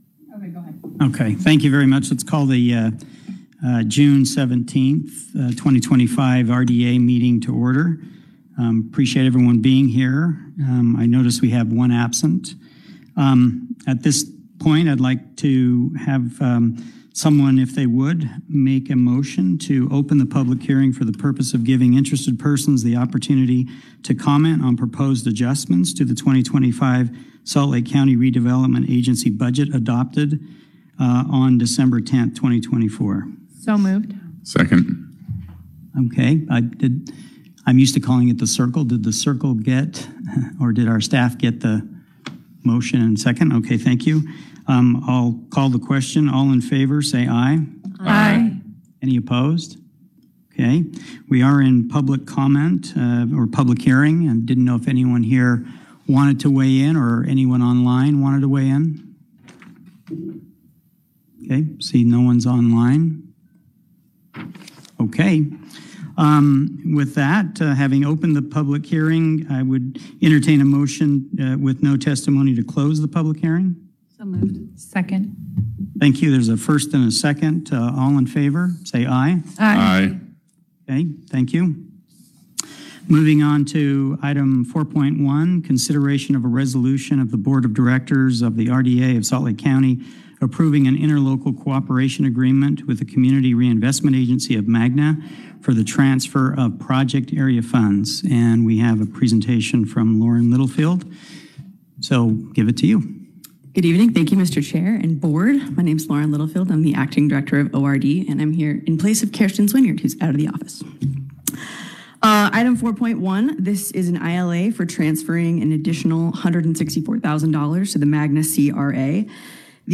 Meeting